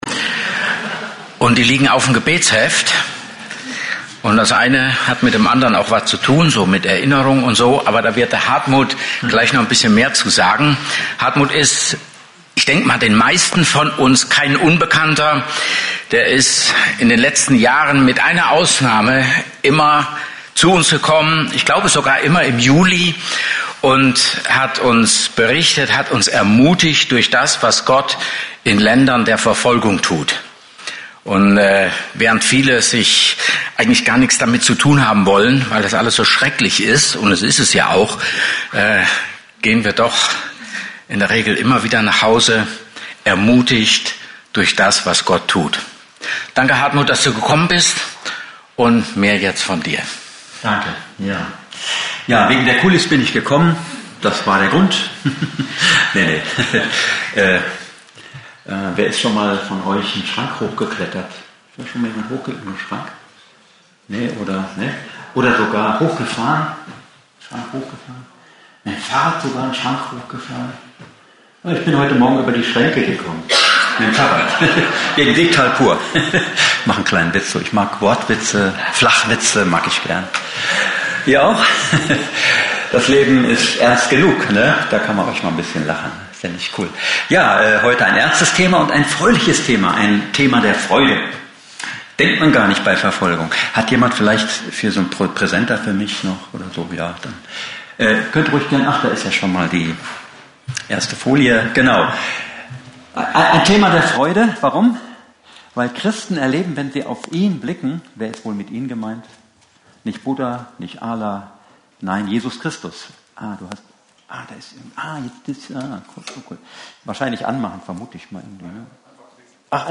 Open Doors Gottesdienst